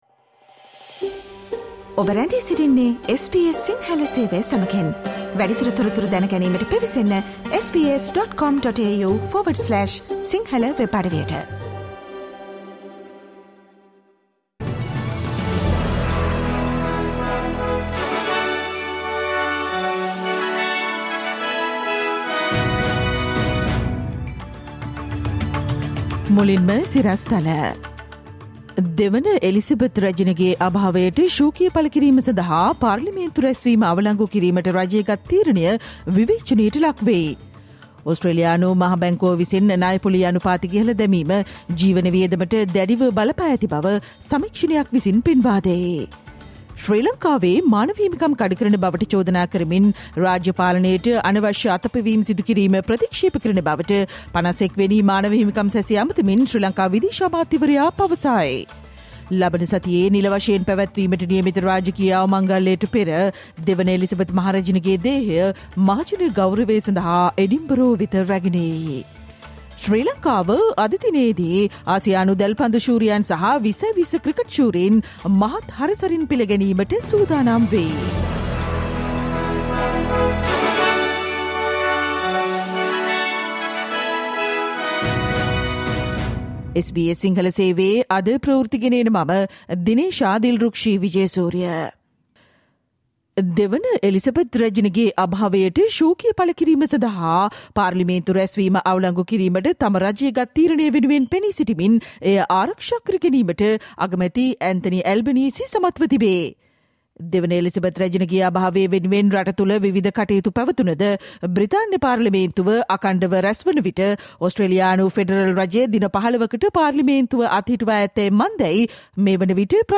Listen to the SBS Sinhala Radio news bulletin on Tuesday 13 September 2022